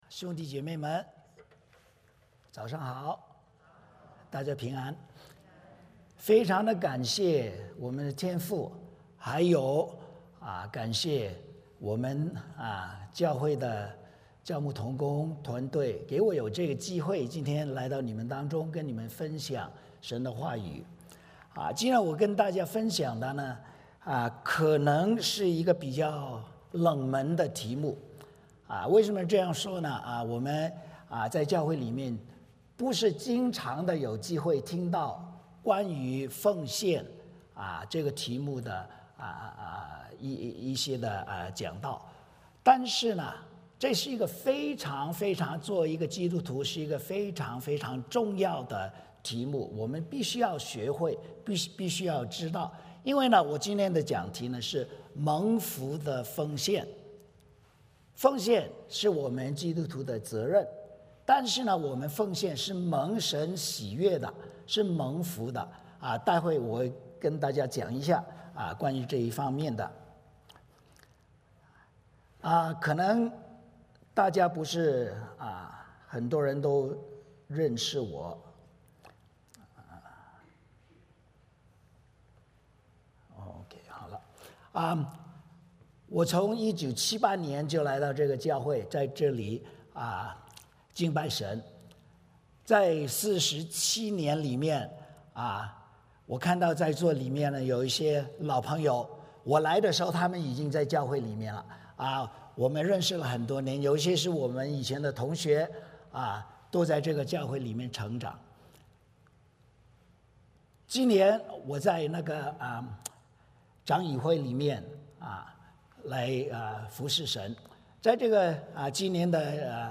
玛拉基书 3:7-10 Service Type: 主日崇拜 欢迎大家加入我们的敬拜。